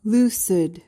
PRONUNCIATION: (LOO-sid) MEANING: adjective: 1.